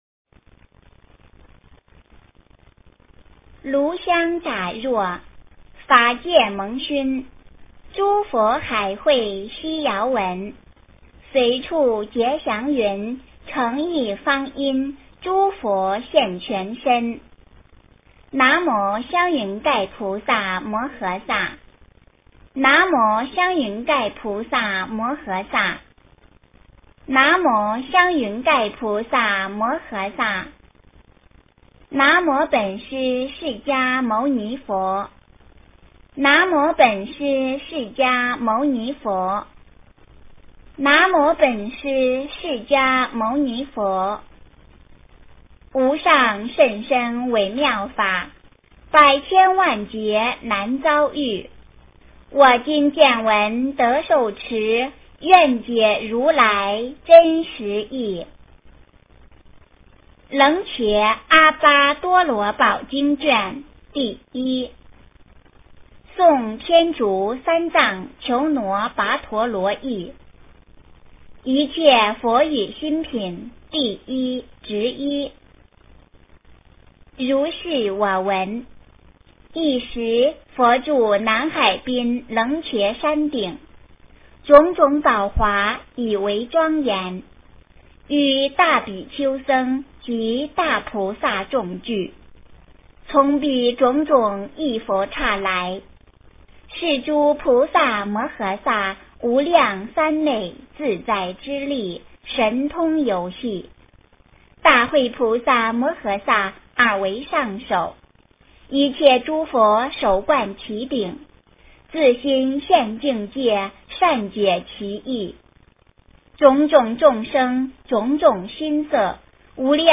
楞伽阿跋多罗宝经1 诵经 楞伽阿跋多罗宝经1--未知 点我： 标签: 佛音 诵经 佛教音乐 返回列表 上一篇： 法华经序品 下一篇： 杂阿含经卷四 相关文章 云钟-序曲--何训田 云钟-序曲--何训田...